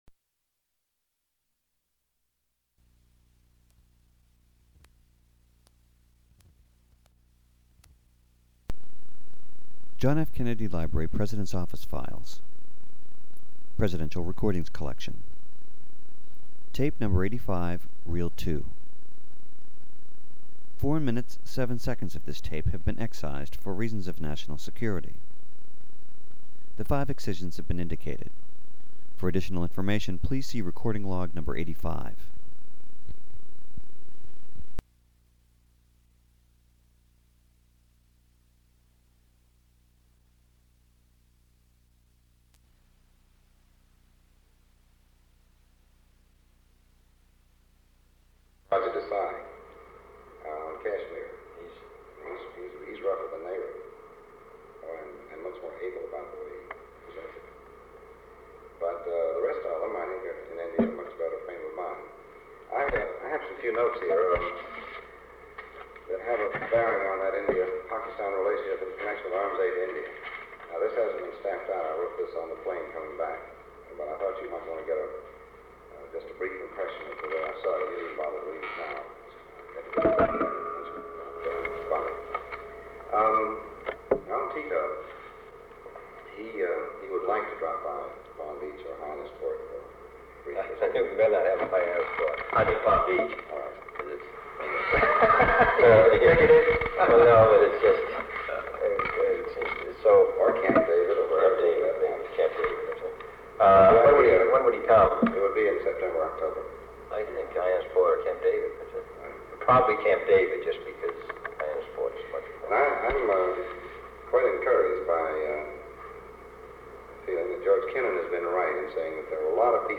Sound recording of a meeting held on May 6, 1963, between President John F. Kennedy, Secretary of State Dean Rusk, and Special Assistant to the President for National Security Affairs McGeorge Bundy. Secretary Rusk reports to the President on his trip to India and Pakistan. Four segments of the recording totaling 2 minutes and 17 seconds have been removed for reasons of national security.